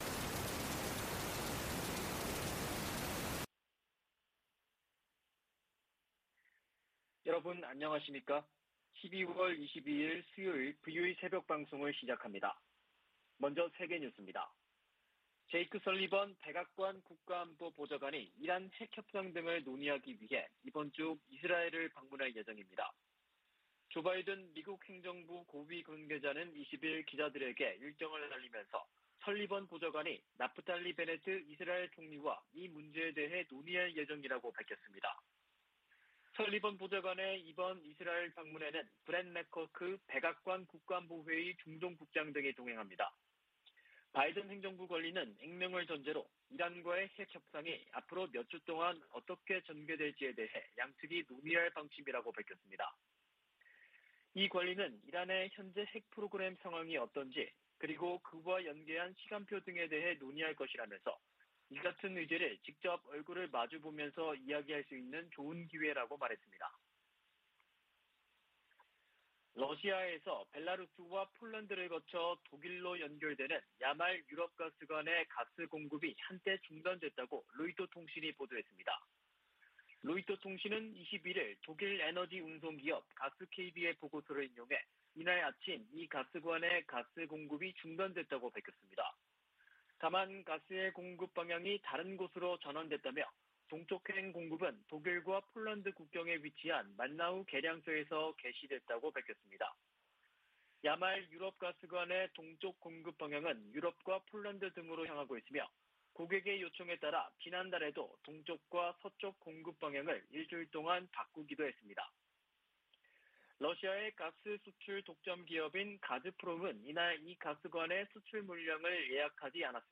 VOA 한국어 '출발 뉴스 쇼', 2021년 12월 22일 방송입니다. 북한은 미사일 개발 등 대규모 군사비 지출로 만성적인 경제난을 겪고 있다고 미 CIA가 분석했습니다. 미국의 전문가들은 북한이 ICBM 대기권 재진입과 핵탄두 소형화 등 핵심 기술을 보유했는지에 엇갈린 견해를 내놓고 있습니다. '오미크론' 변이 출현으로 북-중 교역 재개가 지연됨에 따라 북한 주민들이 겨울나기에 한층 어려움을 겪고 있습니다.